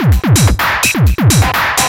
DS 127-BPM A2.wav